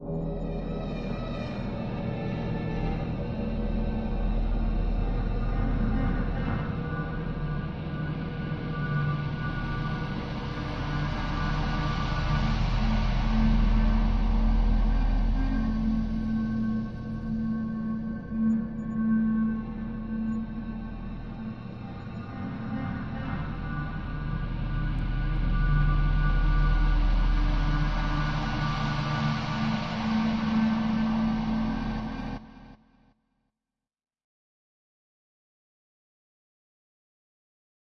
黑暗悬疑
描述：一个黑暗悬疑的无人机
Tag: 空间 悬疑 无人驾驶飞机 恐怖 吓人